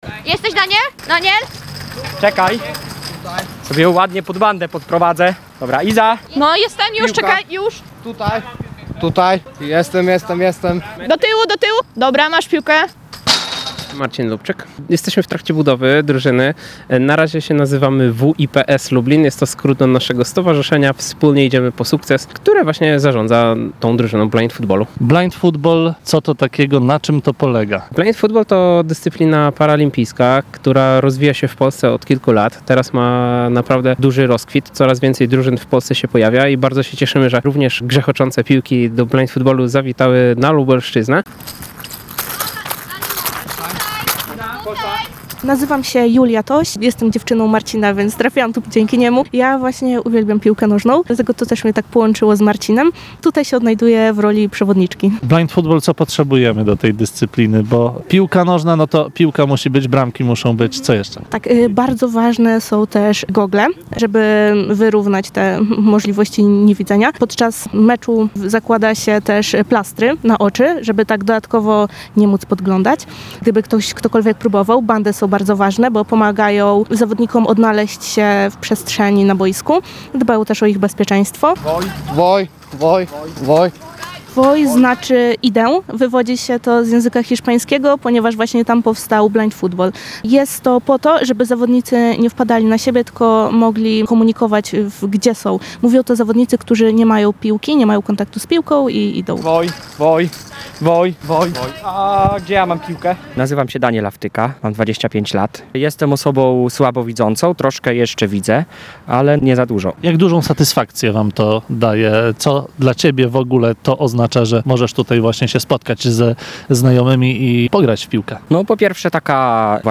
W Lublinie przed kilkoma miesiącami powstała drużyna dla niewidomych i niedowidzących. W jej treningu uczestniczył nasz reporter.